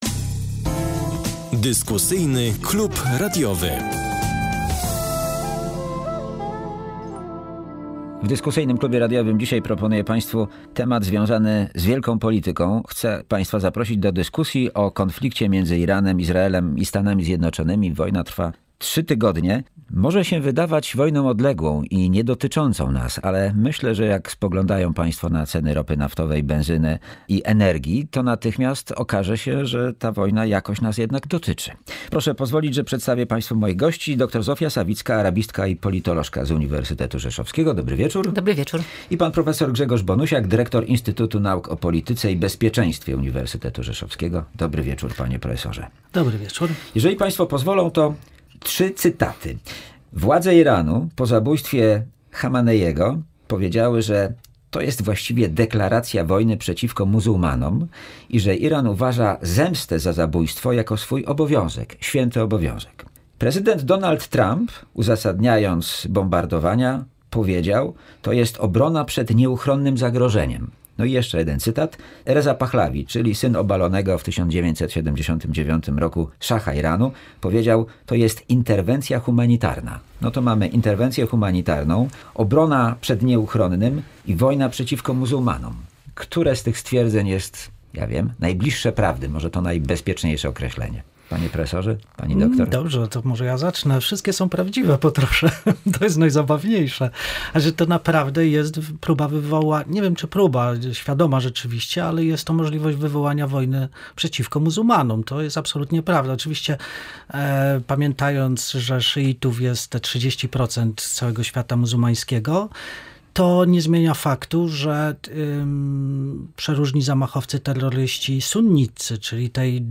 Eksperci analizowali przyczyny, przebieg oraz możliwe skutki tej sytuacji.